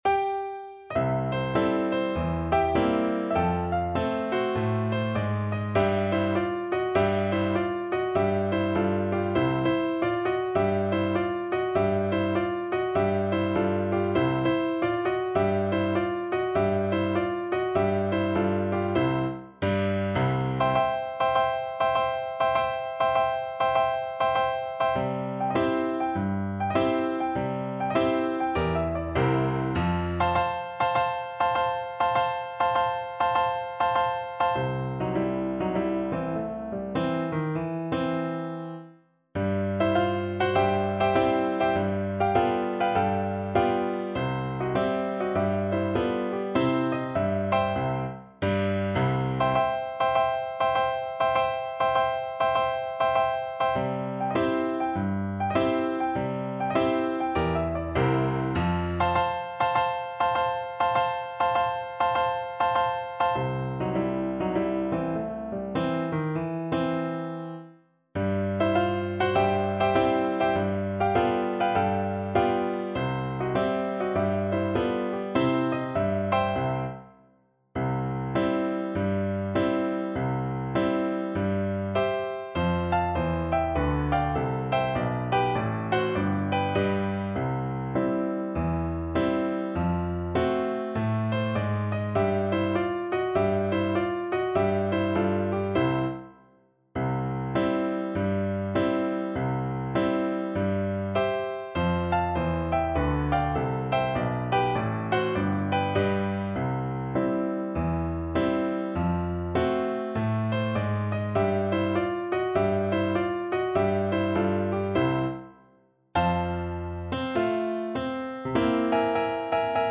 4/4 (View more 4/4 Music)
Jazz (View more Jazz French Horn Music)